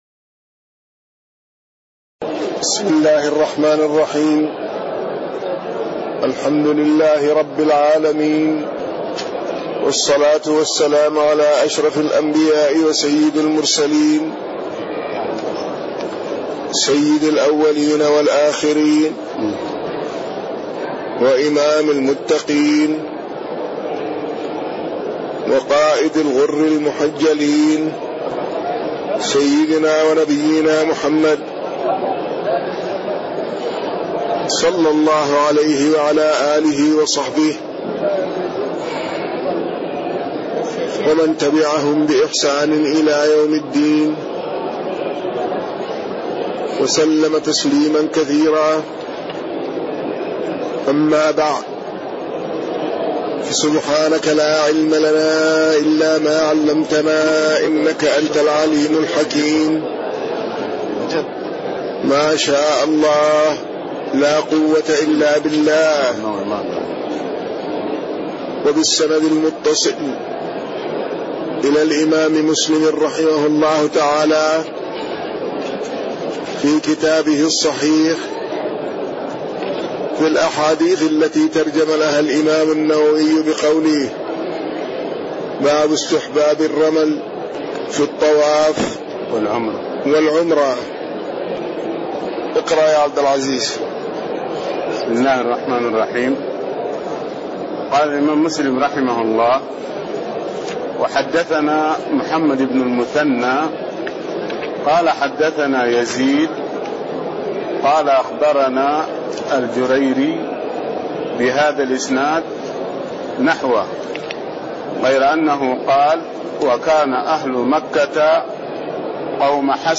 تاريخ النشر ٢٦ محرم ١٤٣٤ هـ المكان: المسجد النبوي الشيخ